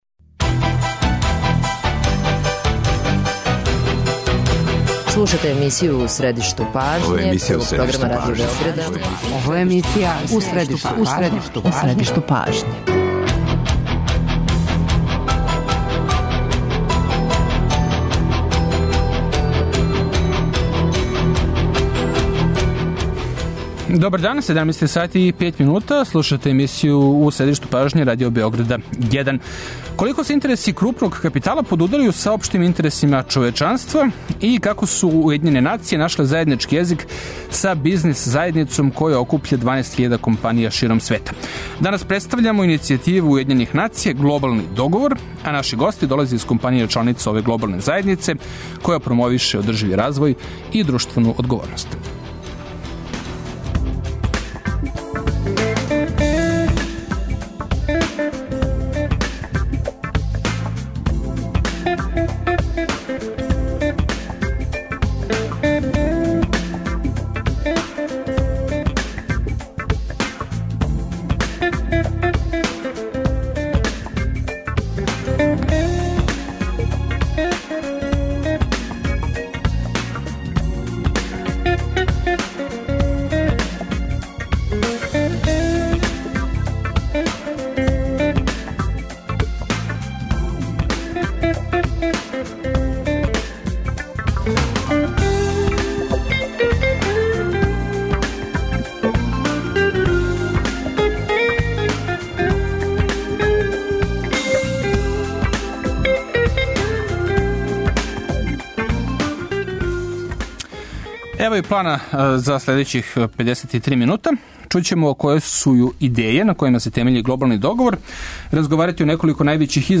Гости емисије су представници компанија Сименс, КПМГ, Делта и Смарт Колектив, које су чланице су Глобалног договора.